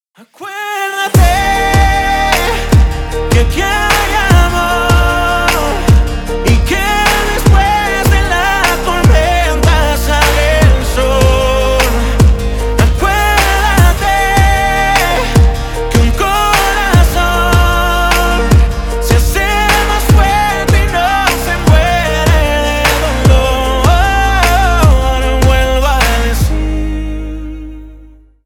• Качество: 320 kbps, Stereo
Поп Музыка
латинские